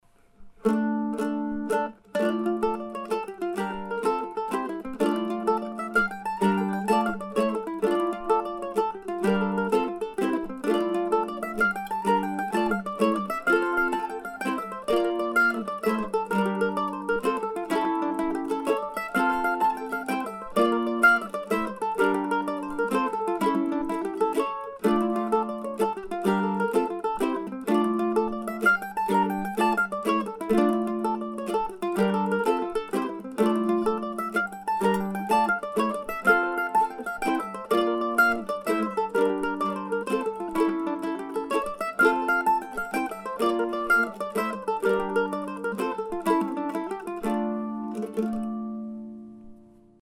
"Pocket Change" is a fun slip jig and feels good to play once you get it under your fingers (at least on the mandolin).